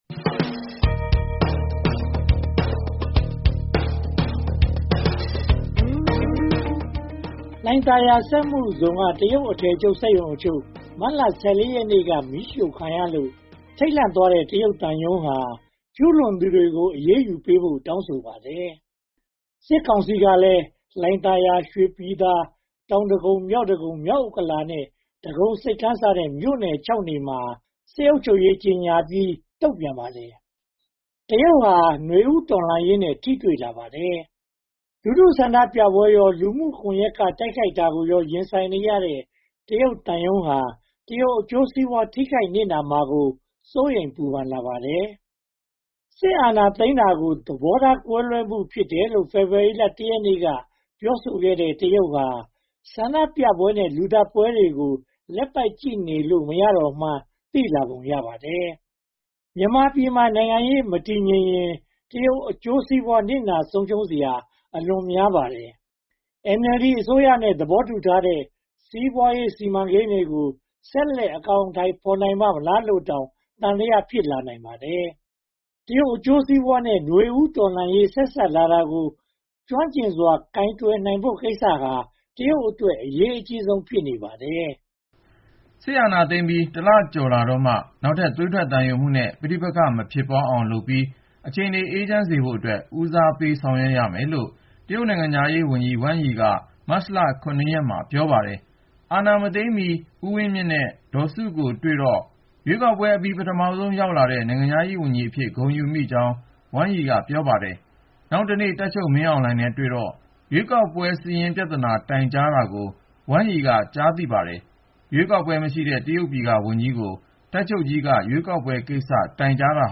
သတင်းသုံးသပ်ချက်